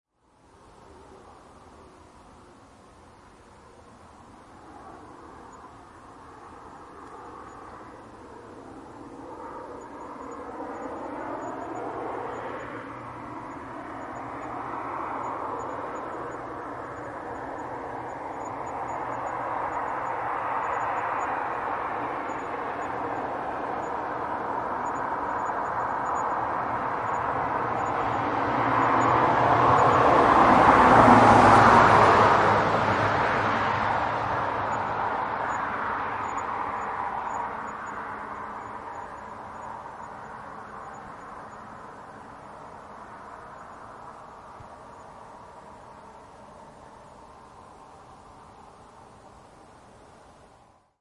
0015 轿车驶过RL
描述：汽车从高速公路从右到左开车。
Tag: 公路 现场记录 汽车 driveby